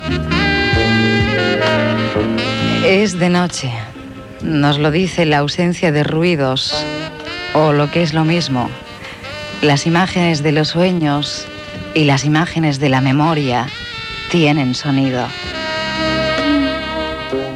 Presentació del programa amb esment a la nit.